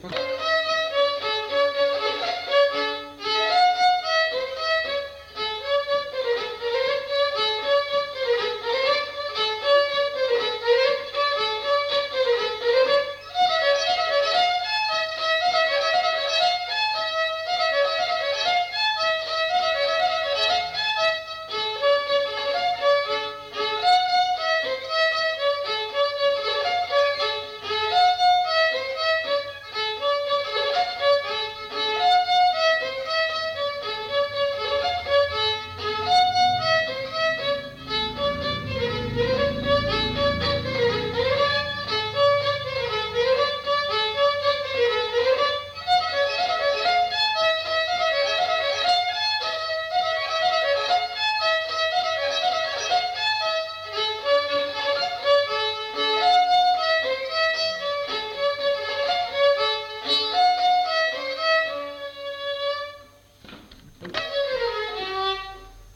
Aire culturelle : Petites-Landes
Lieu : Roquefort
Genre : morceau instrumental
Instrument de musique : violon
Danse : congo